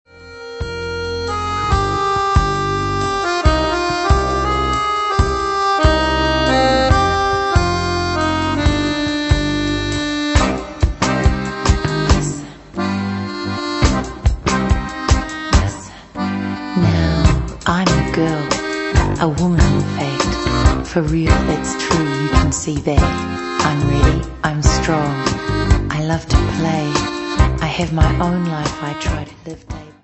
at once heartfelt and uplifting, intimate and wild.